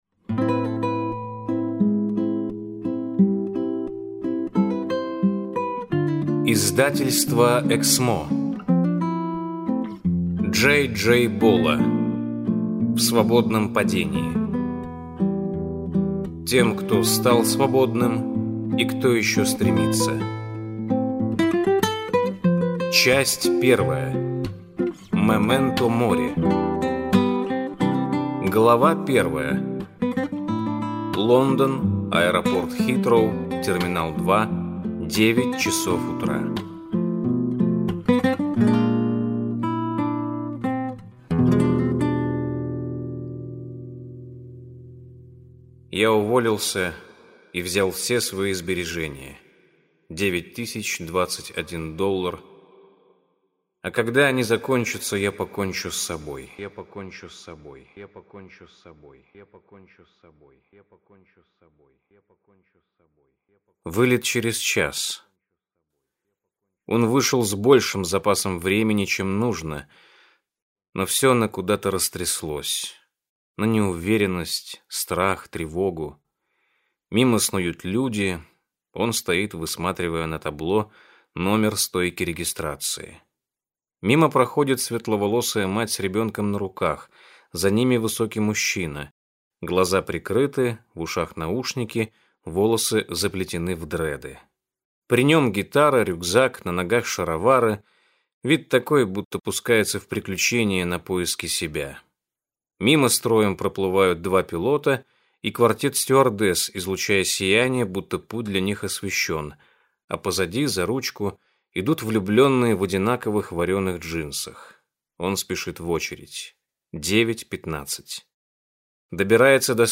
Аудиокнига В свободном падении | Библиотека аудиокниг